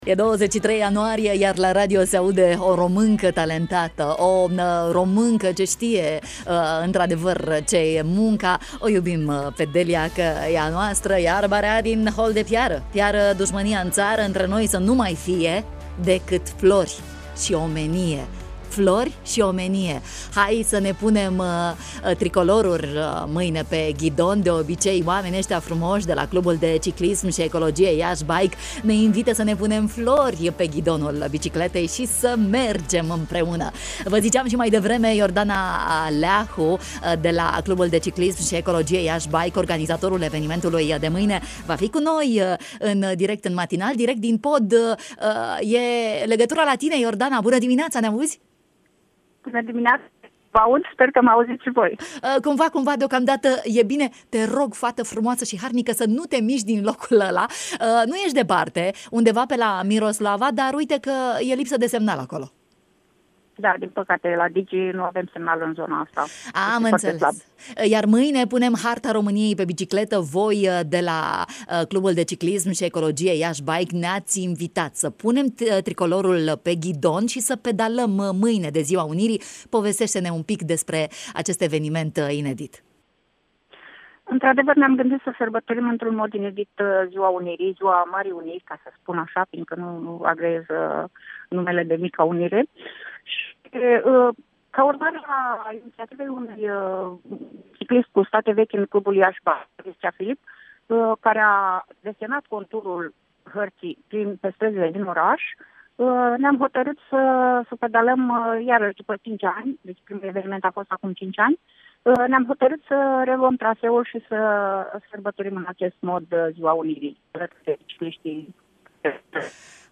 În direct la matinalul de la Radio România Iași